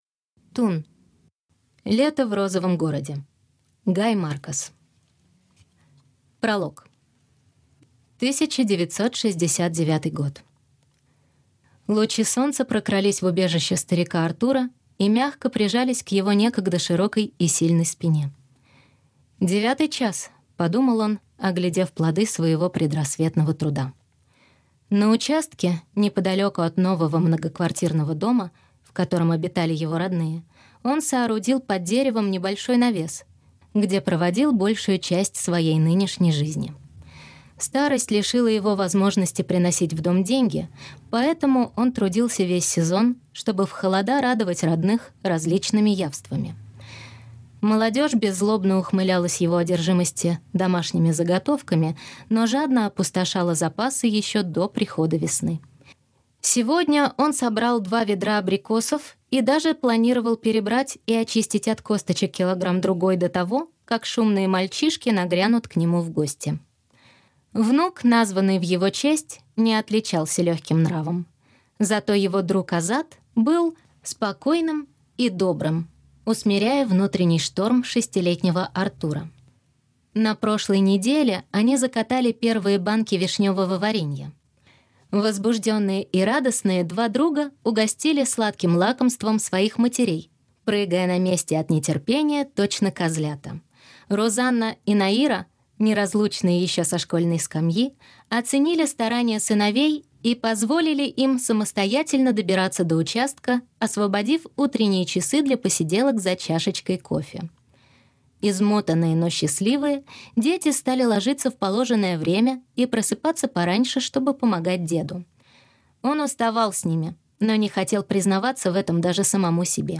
Аудиокнига Тун. Лето в розовом городе | Библиотека аудиокниг
Прослушать и бесплатно скачать фрагмент аудиокниги